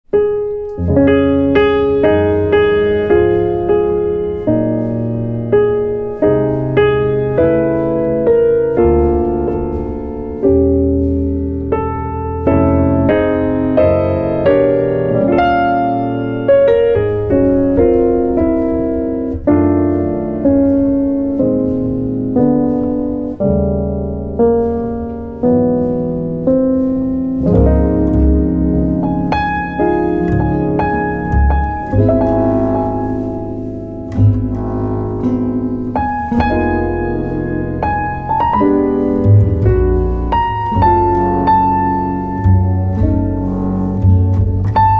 Piano
Guitar